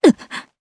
Ripine-Vox_Damage_jp_01.wav